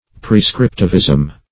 prescriptivism \pre*scrip"tiv*ism\, n.